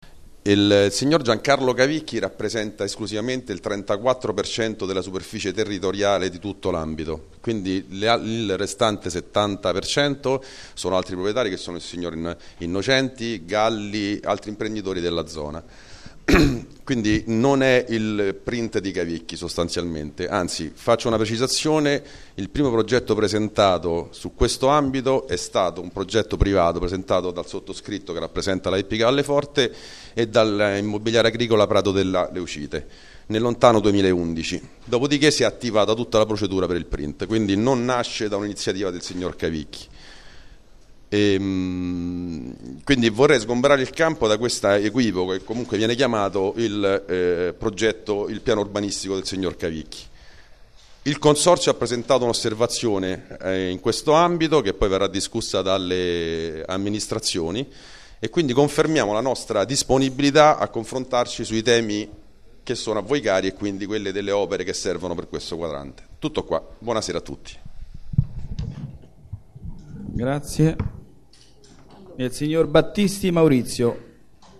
Registrazione integrale dell'incontro svoltosi il 15 luglio 2014 presso la Sala Rossa del Municipio VII, in Piazza di Cinecittà, 11